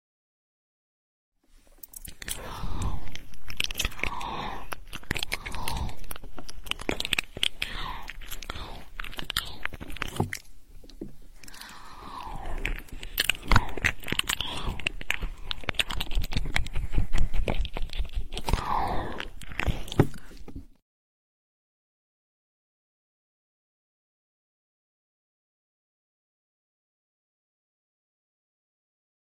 ASMR Ear Biting sound effects free download